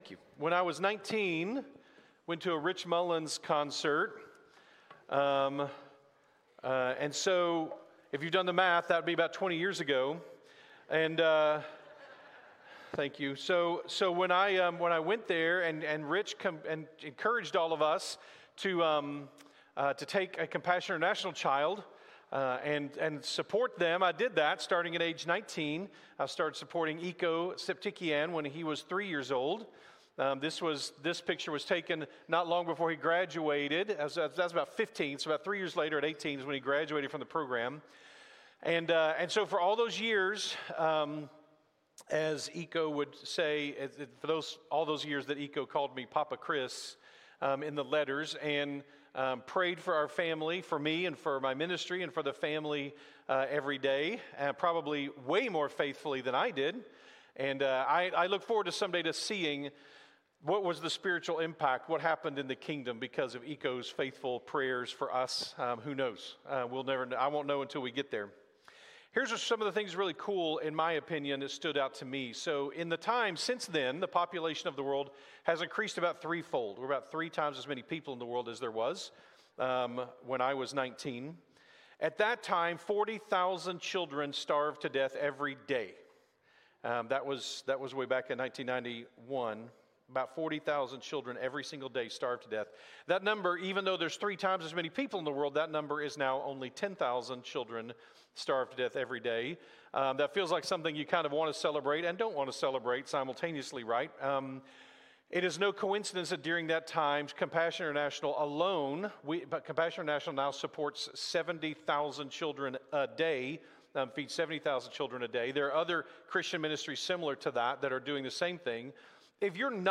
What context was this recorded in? September-7-2025-Sunday-Morning.mp3